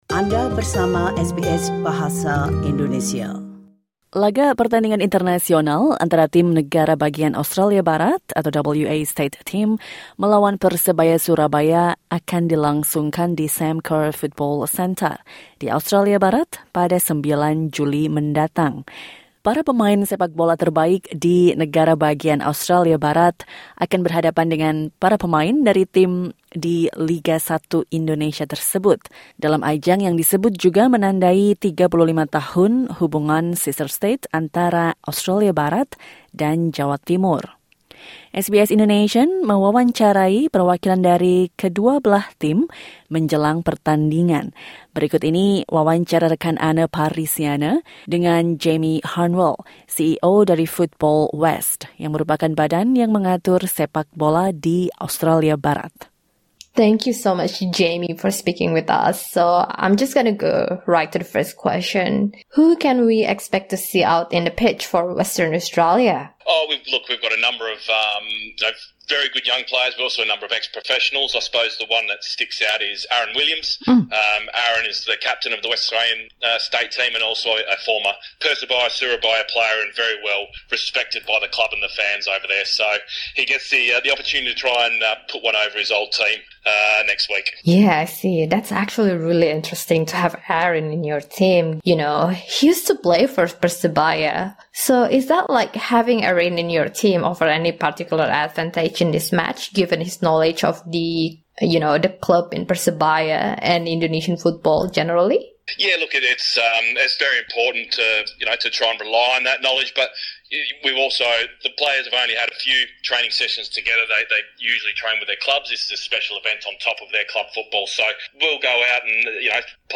SBS Indonesian mewawancarai perwakilan dari kedua belah tim jelang pertandingan ini